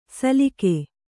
♪ salike